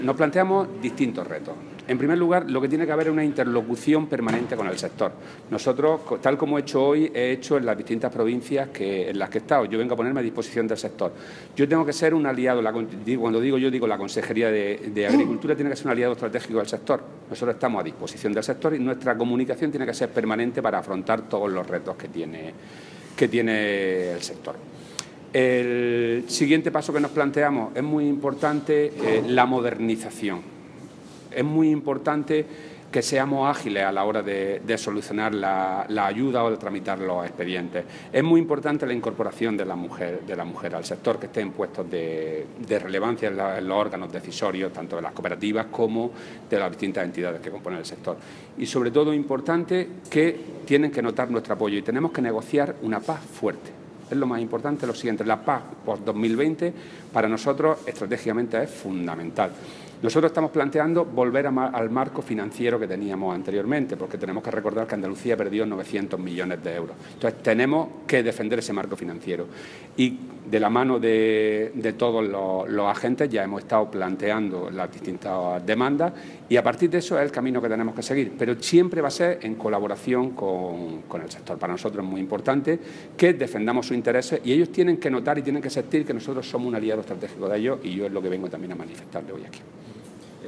Declaraciones de Rodrigo Sánchez sobre el sector